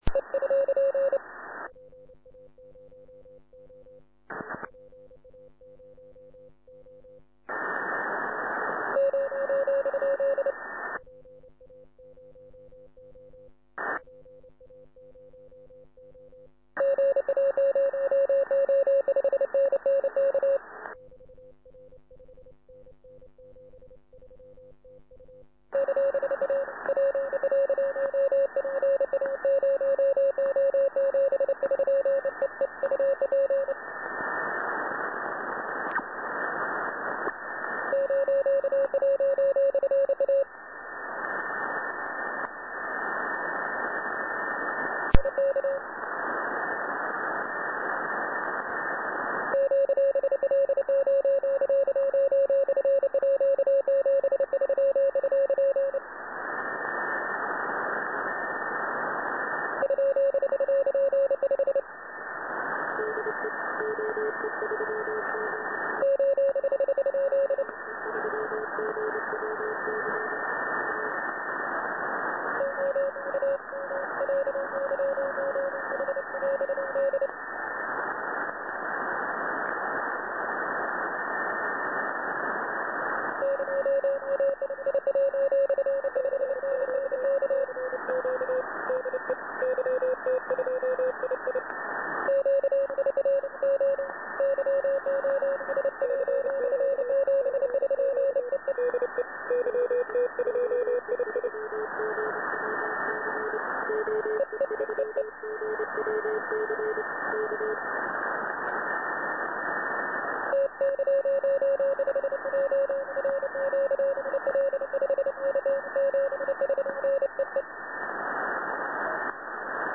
A good signal and long opening after 3 previous ones passed empty.
big pile with  DL OK OM YU etc etc many callers strong heard in the QSX
Pff  this time the signal came back in to about 569 good signal with big DL OK YU etc pile.